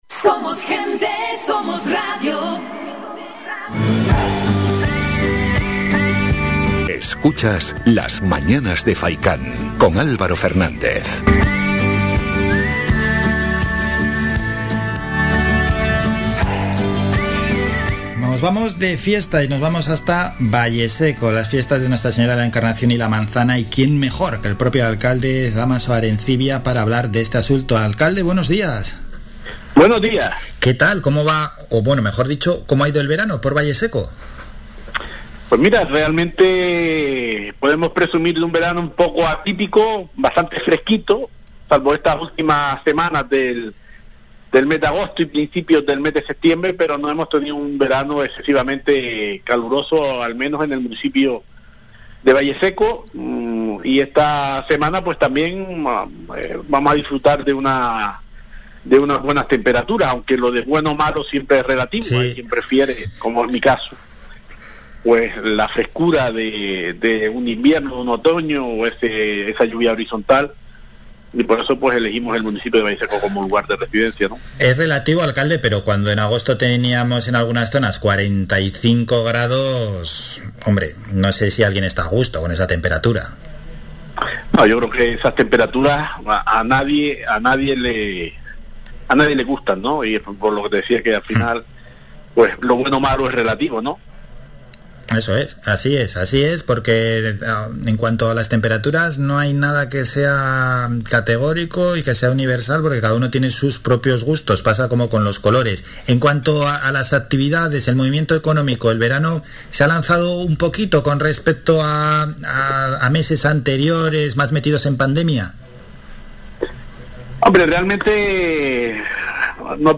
Entrevista a Dámaso Arencibia, nos presenta las fiestas de Valleseco - Radio Faycán
El alcalde de Valleseco, Dámaso Arecibia intervino en Las Mañanas de Faycán para presentar a nuestra audiencia las fiestas de Valleseco 2021.